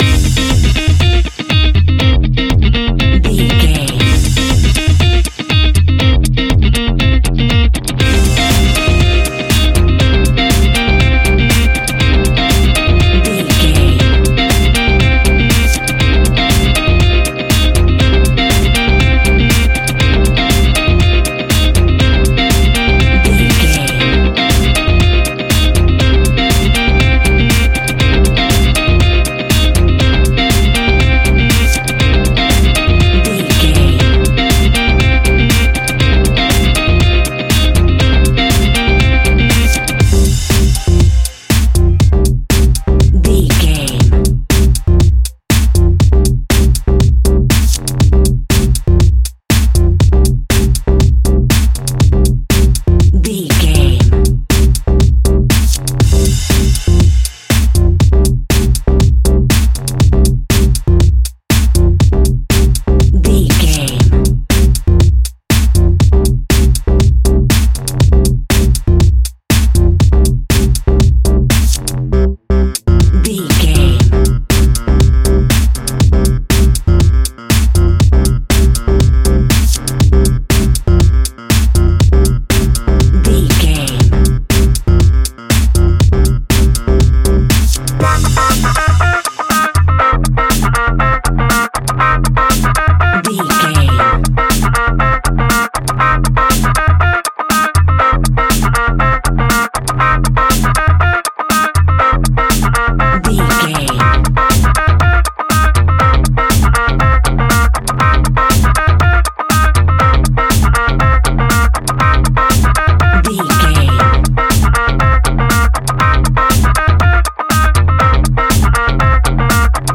Aeolian/Minor
intense
energetic
repetitive
electric guitar
bass guitar
drum machine
piano
funky house
electro funk
upbeat
synth leads
Synth Pads
synth bass